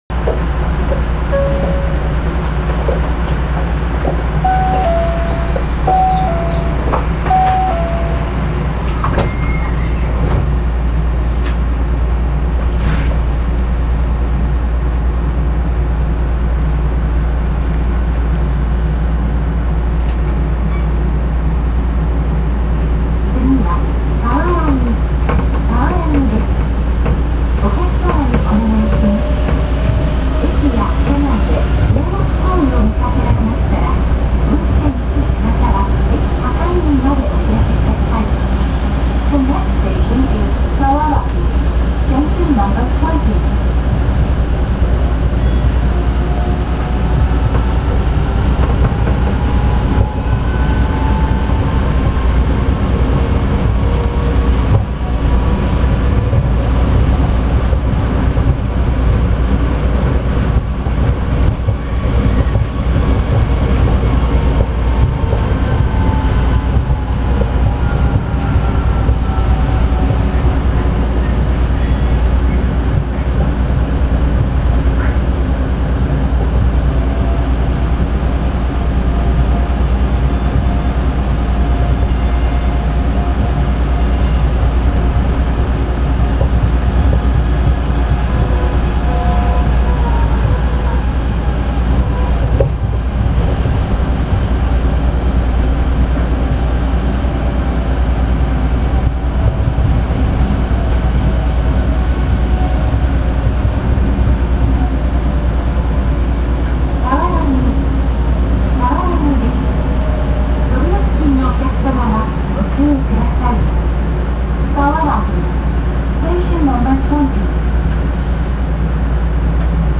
・1000系走行音
【本線】南茨木〜沢良宜（2分14秒：729KB）…1103Fにて
界磁チョッパ式の走行装置で、モーター音は大変大人しく、目立ちません。停車中には700系以降の新幹線で聞くことのできるチャイムが流れ、ドアチャイムは阪急と同じ。